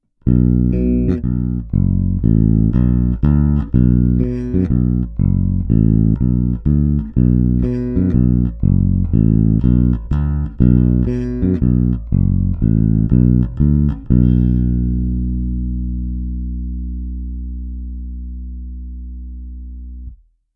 I já jsem nahrál zvukové ukázky, ovšem se stávajícími snímači Bartolini.